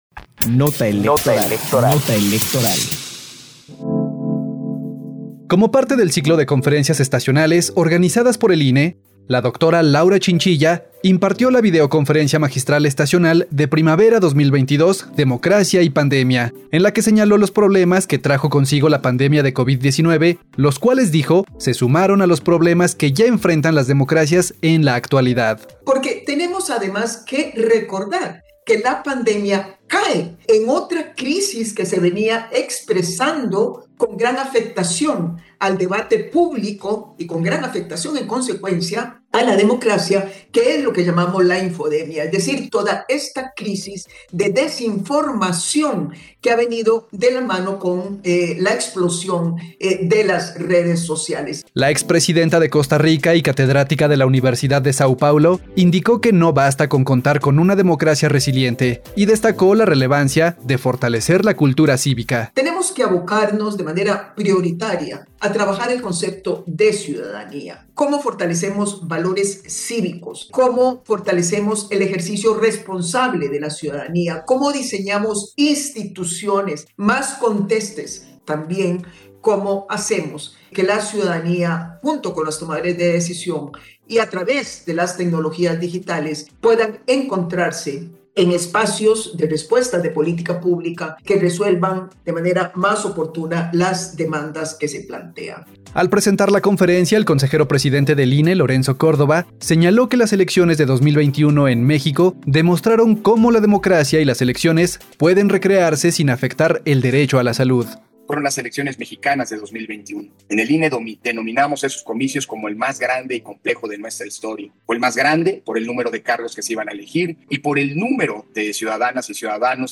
Imparte Laura Chinchilla Conferencia Magistral Estacional de Primavera 2022 “Democracia y Pandemia”